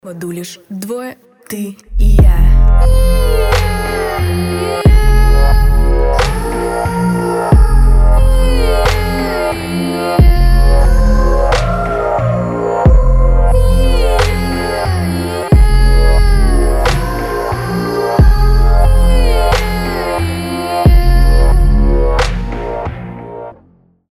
alternative
необычные
мрачные
Необычная поп-песня